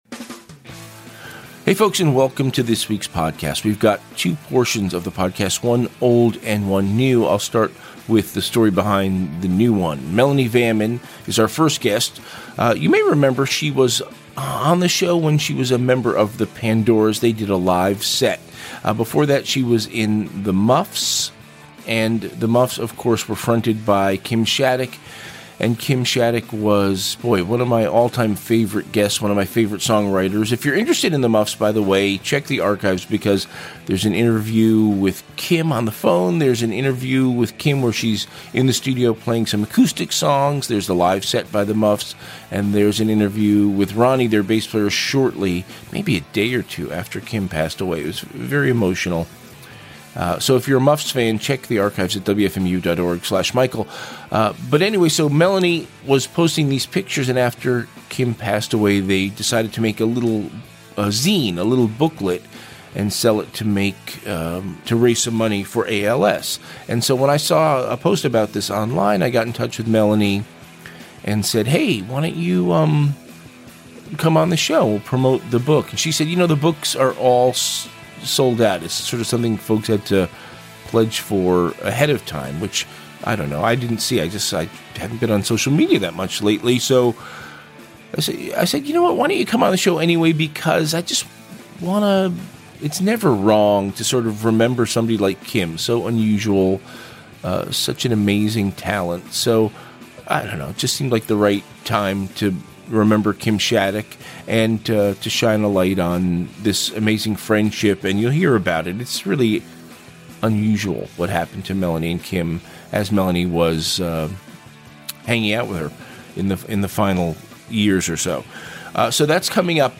"Interview"